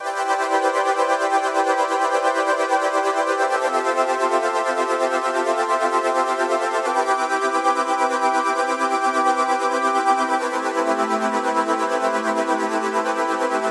描述：低音合成器垫
标签： 70 bpm Ambient Loops Pad Loops 2.31 MB wav Key : E
声道单声道